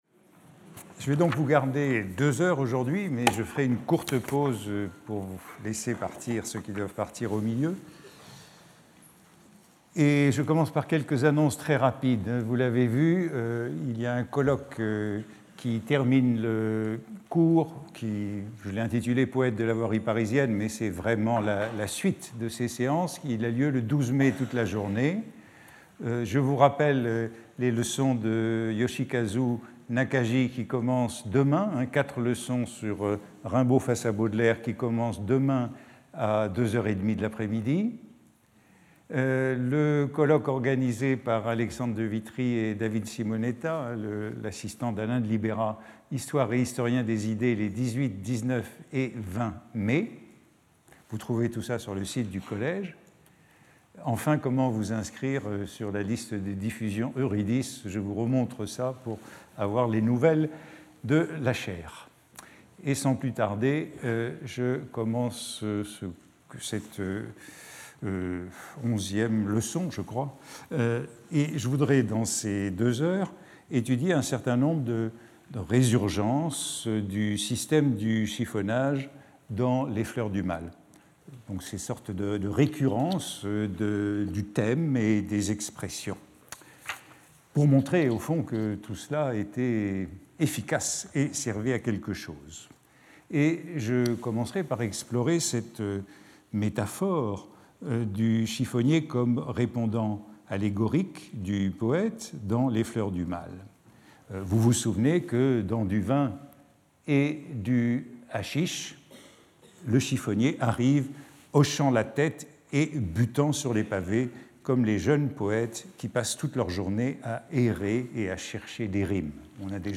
Speaker(s) Antoine Compagnon Professor at the Collège de France